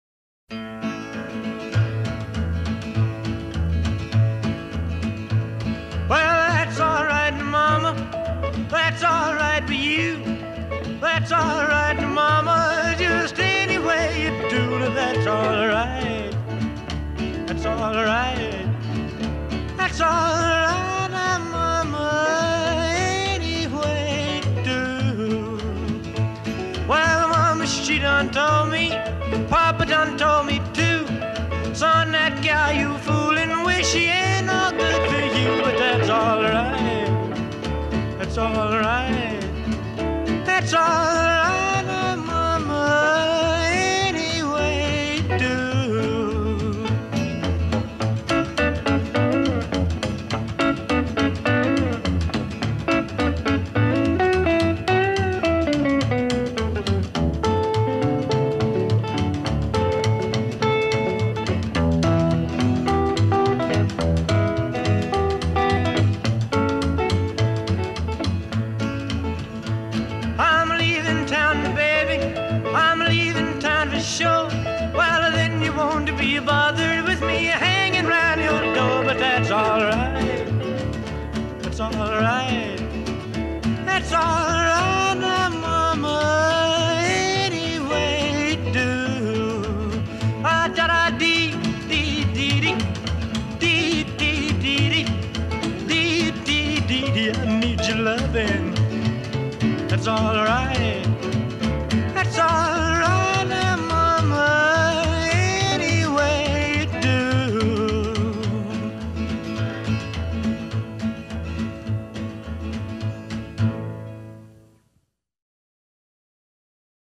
Rock & Roll, Pop, Early Rock